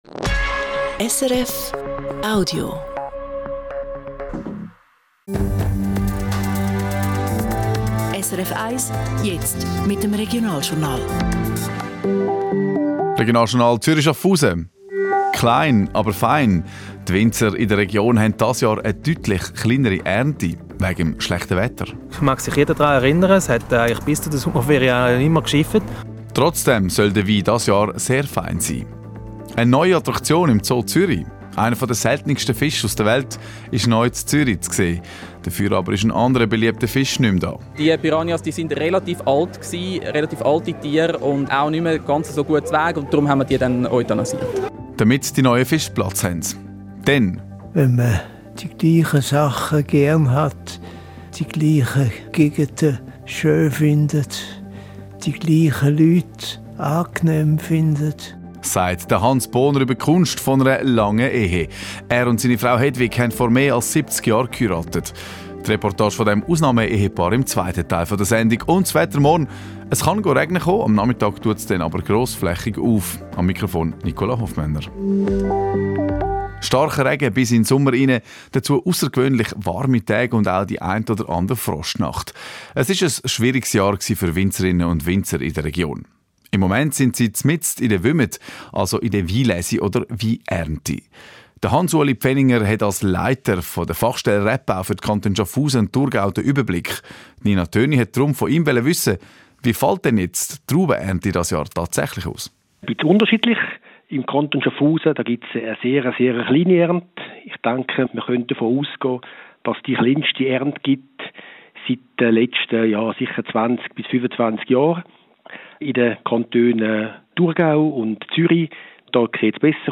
SRF – Das Radio war auf Besuch an der Wümmet – HerterWein
Regional Journal Die Reportage startet ab Minute 5:45.